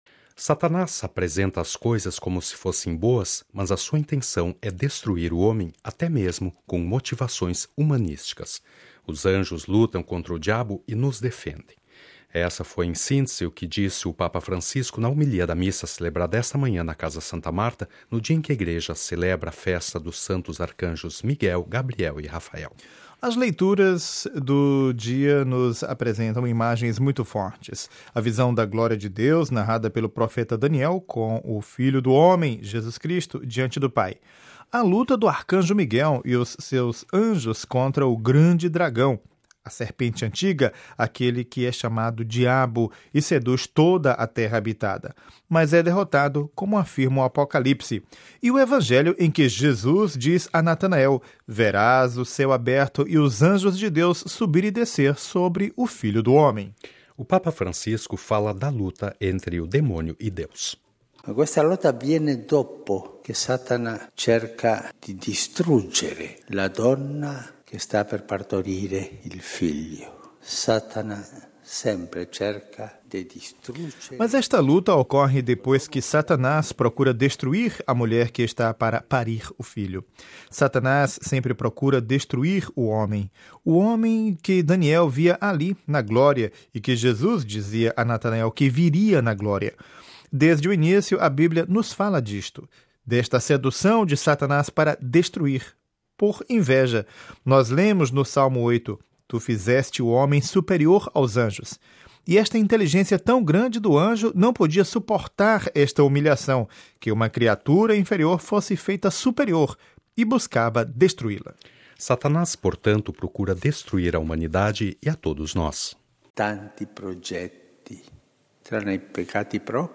Os anjos lutam contra o diabo e nos defendem. Isto foi, em síntese, o que disse o Papa Francisco na homilia da Missa celebrada esta manhã na Casa Santa Marta, no dia em que a Igreja celebra a Festa dos Santos Arcanjos Miguel, Gabriel e Rafael.